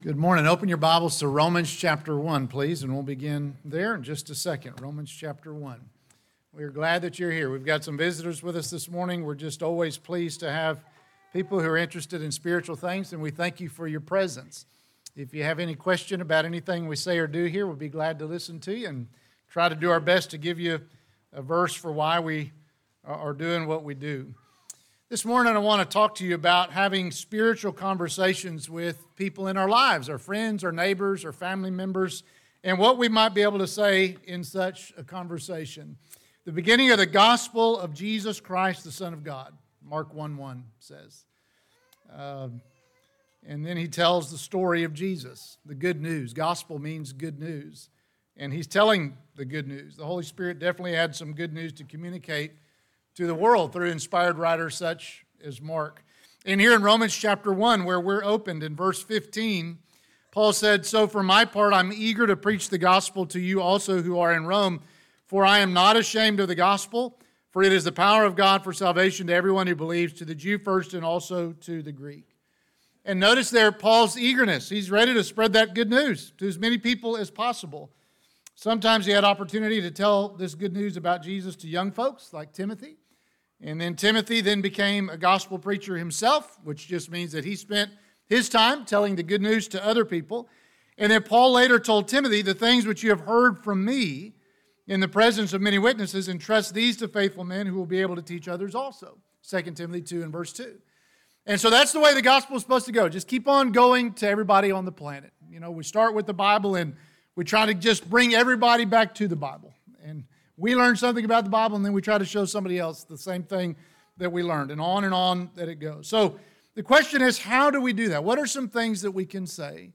Sermons - Benchley church of Christ